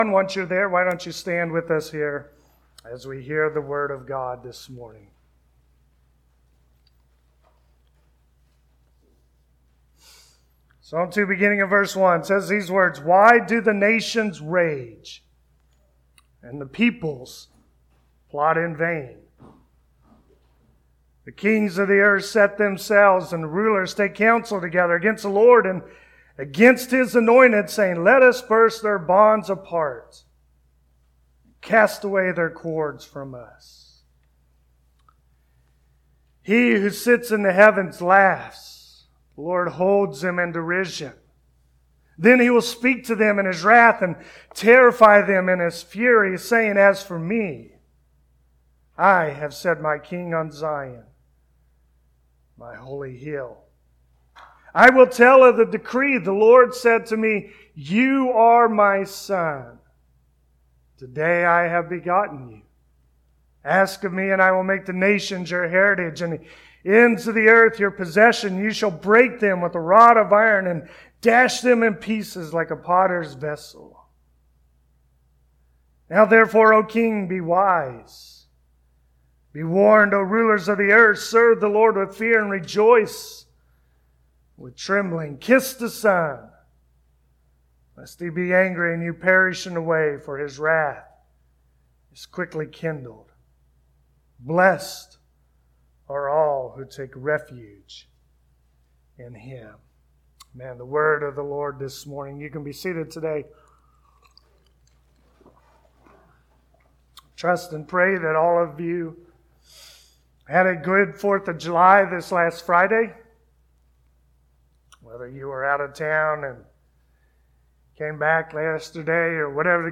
Passage: Psalm 2 Service Type: Sunday Morning As turmoil and chaos encompasses the world’s political scene, Christians can rest assured that God is reigning over all.